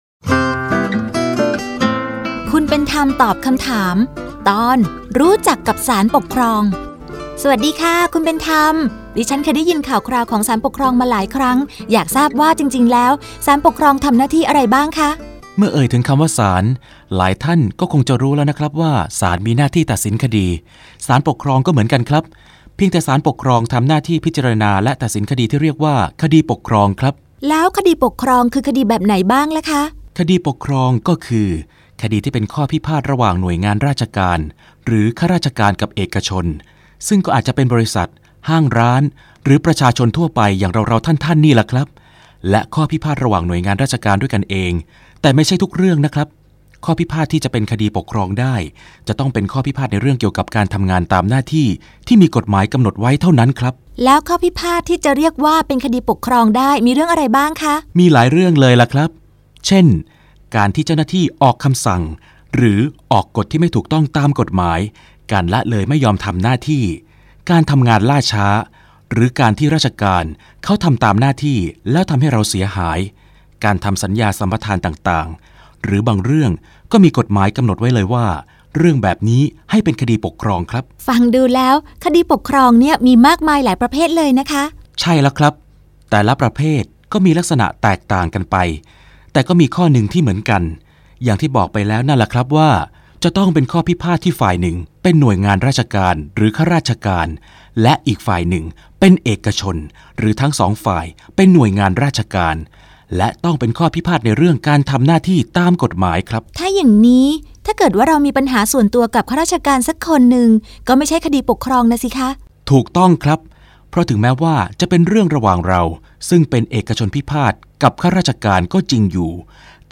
สารคดีวิทยุ ชุด คุณเป็นธรรมตอบคำถาม